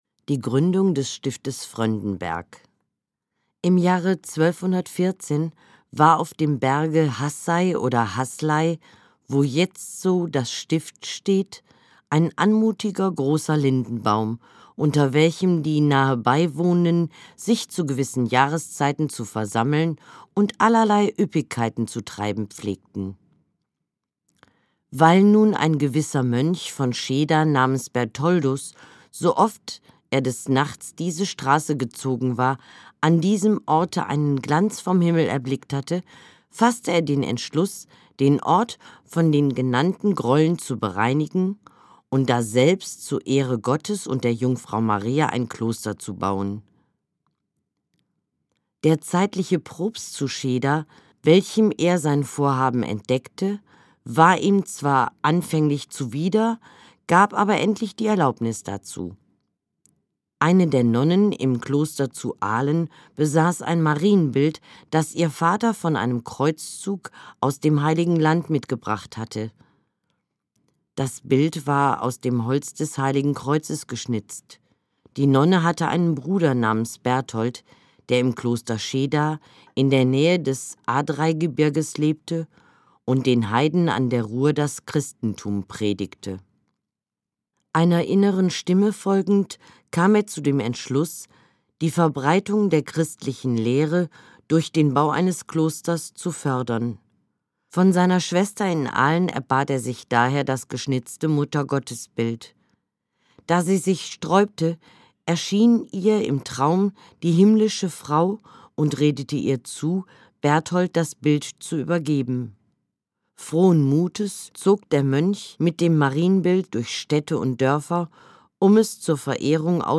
Gelesen